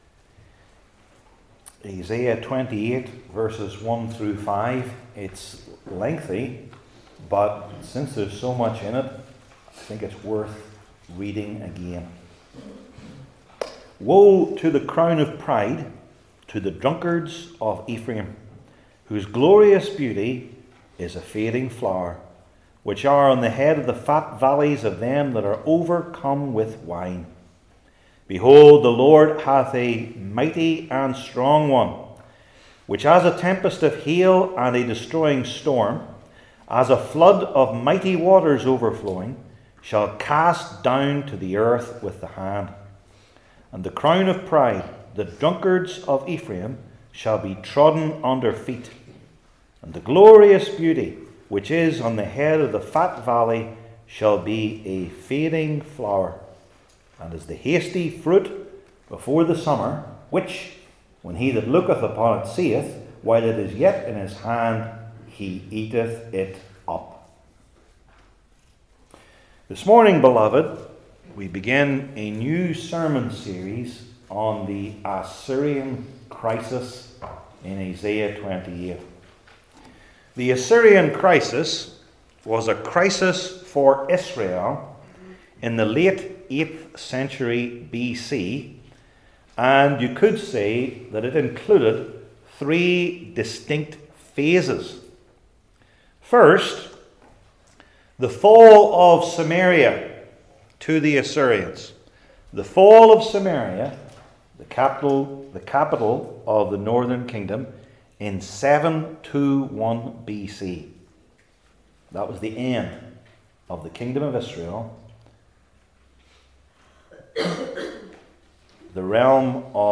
Isaiah 28:1-5 Service Type: Old Testament Sermon Series I. The Dual Imagery II.